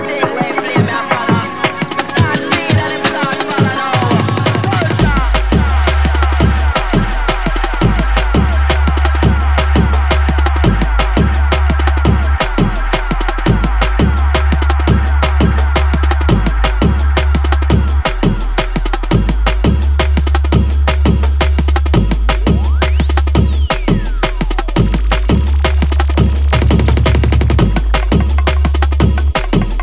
TOP >Vinyl >Drum & Bass / Jungle
bonus Beat